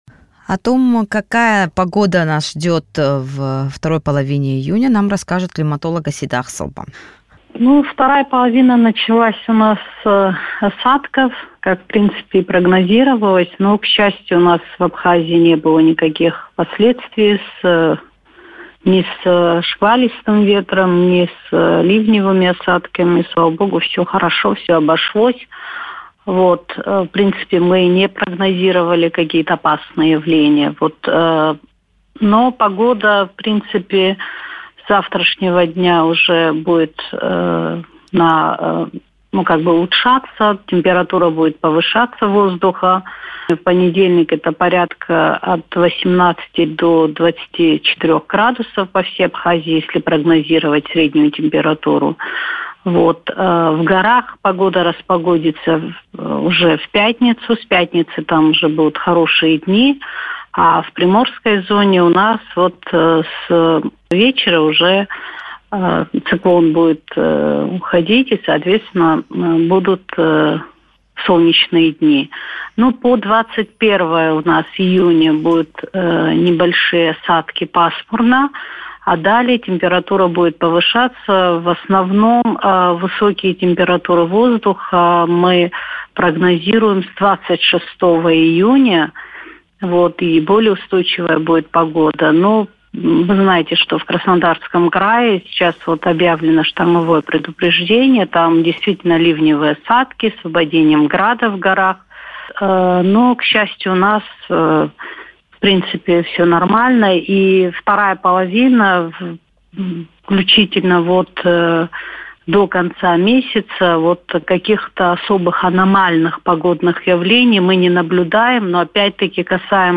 Как долго продолжатся дожди в Абхазии, когда прогреется море и наступит летний зной, в эфире радио Sputnik рассказала климатолог